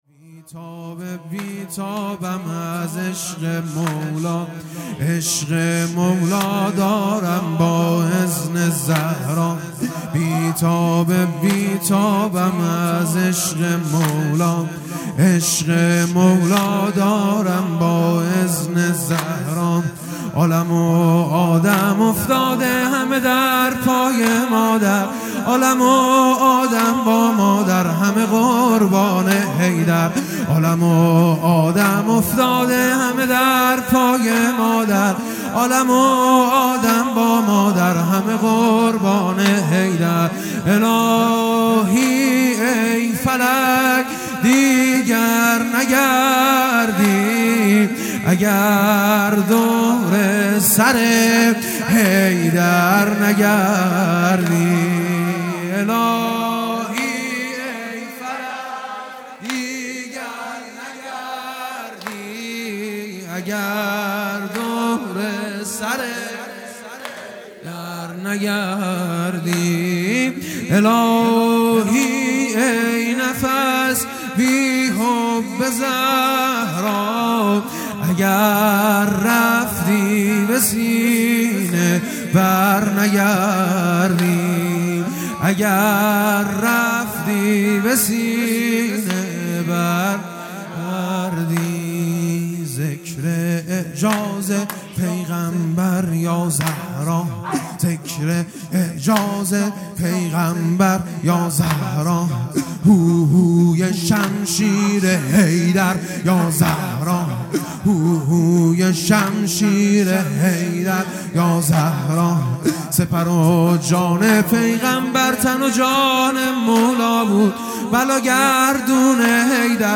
هیئت دانشجویی فاطمیون دانشگاه یزد
بی تاب بی تابم از عشق مولا|جلسه هفتگی